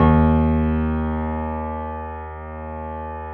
Index of /90_sSampleCDs/USB Soundscan vol.17 - Keyboards Acoustic [AKAI] 1CD/Partition B/02-GRANDP MO